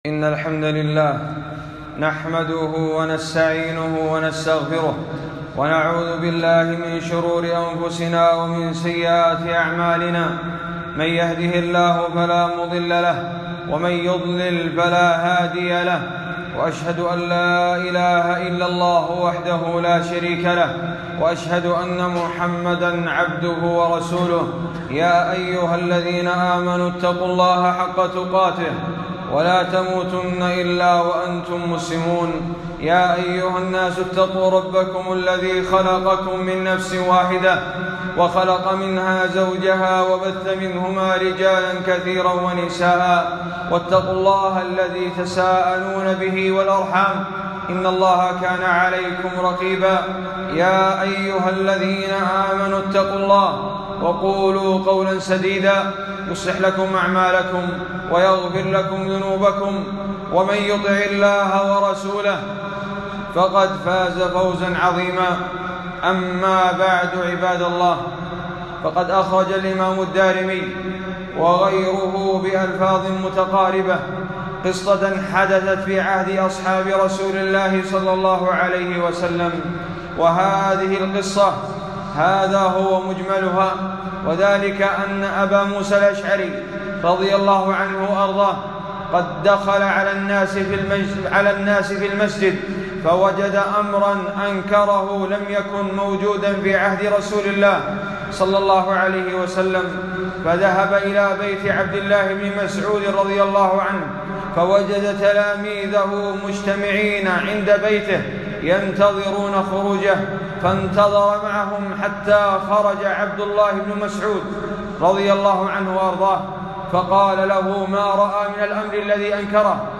خطبة - التحذير من بدعة المولد ومن التستر التجاري